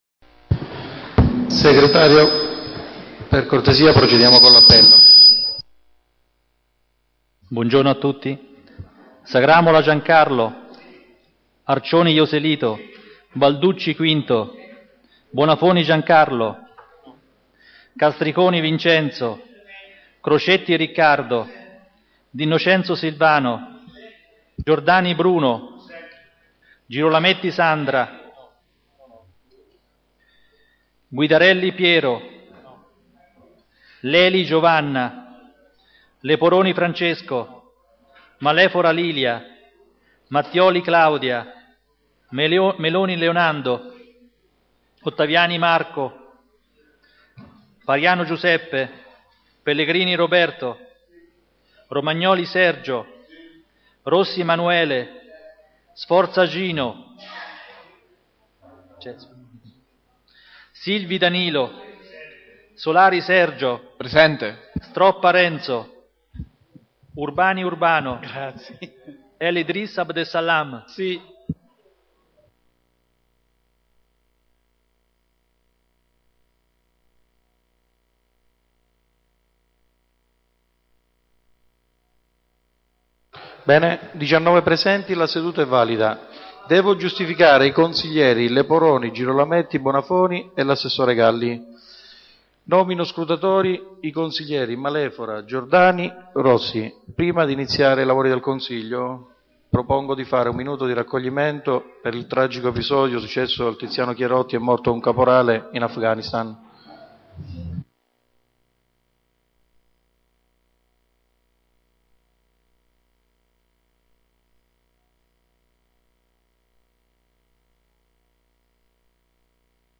Seduta di martedi 30 ottobre 2012
Ai sensi dell`art. 20, comma 7, dello Statuto Comunale e dell`articolo 14 del regolamento consiliare, il Consiglio Comunale e` convocato presso Palazzo Chiavelli - sala consiliare martedi 30 ottobre alle ore 9.00